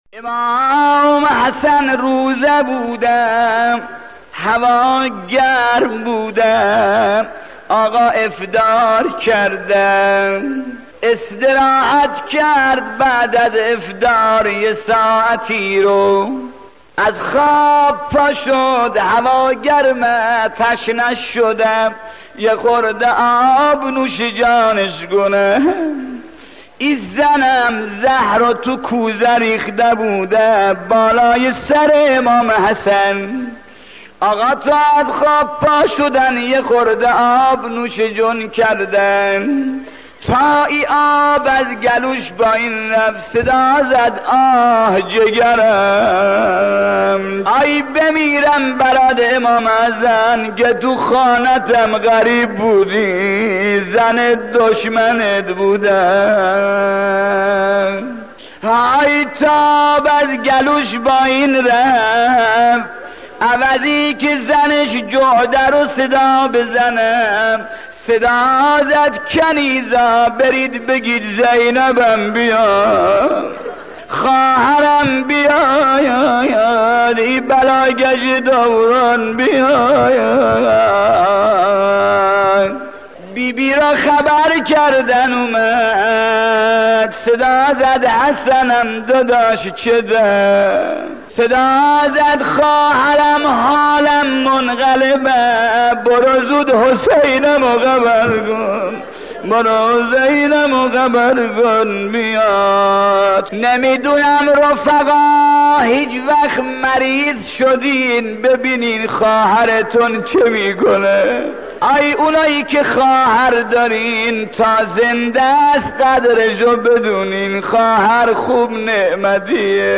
روضه شهادت کریم اهل بیت(ع)